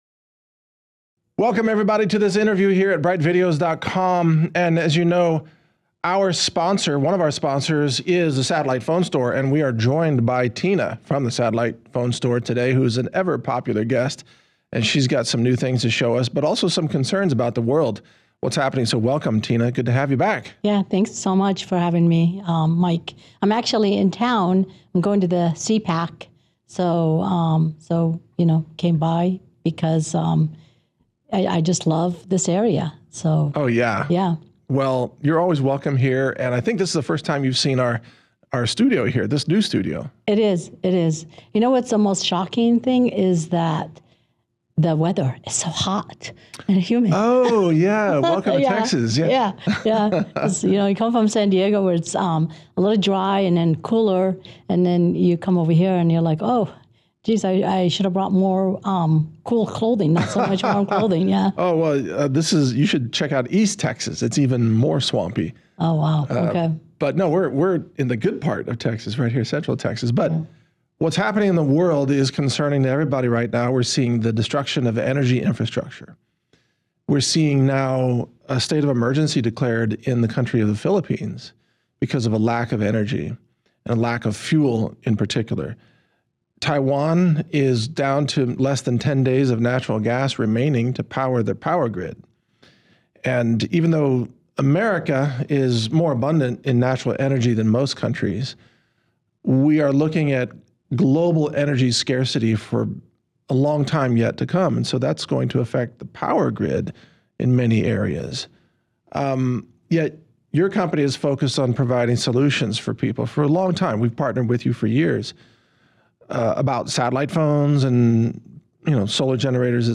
Natural News Radio
Interviews